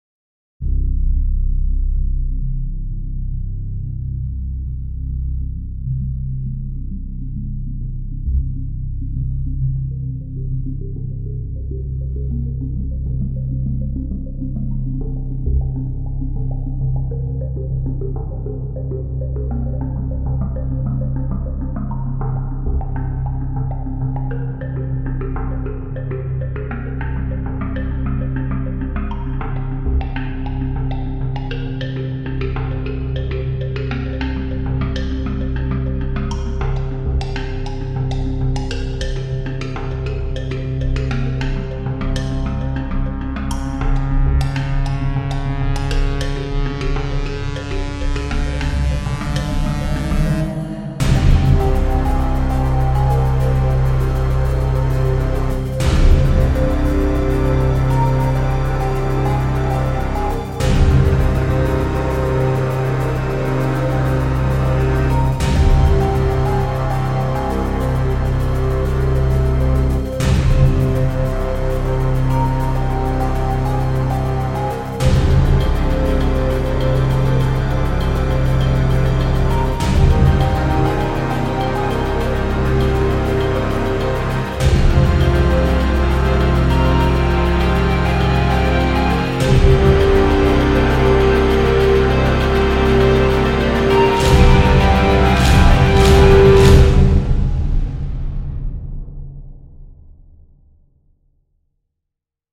-小雨，中雨和大雨有多种变化
-远处的雨和风具有多种变化
单个补丁可以使小雨慢慢转变成咆哮的风暴。